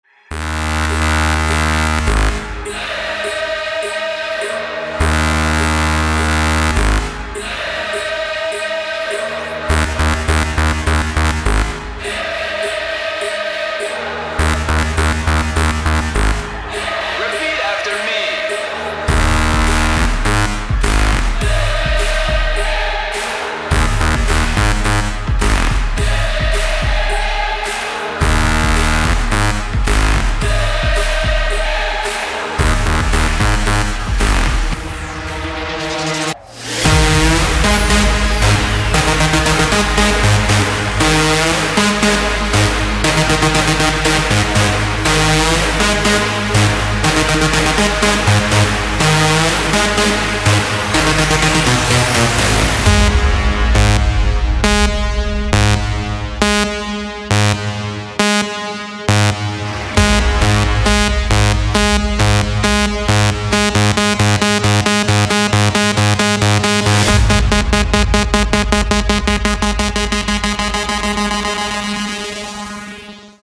• Качество: 224, Stereo
мужской голос
громкие
dance
Electronic
EDM
электронная музыка
нарастающие
club
Trance
хор